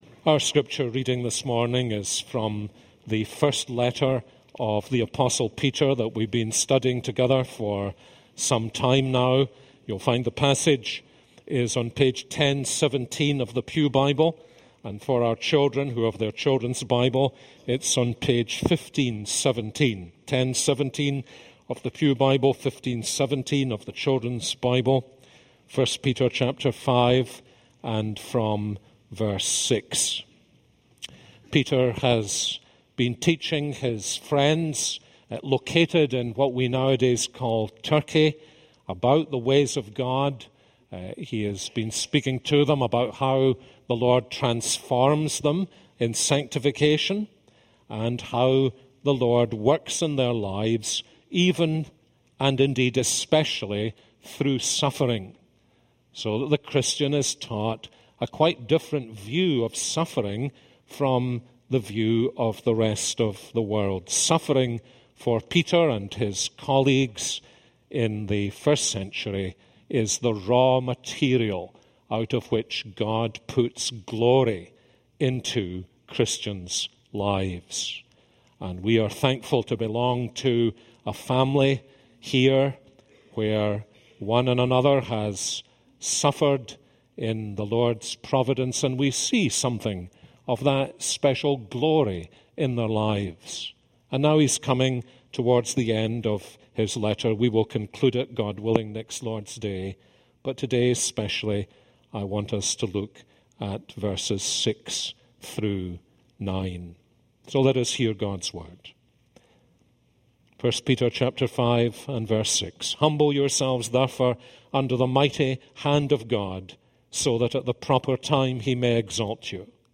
This is a sermon on 1 Peter 5:6-9.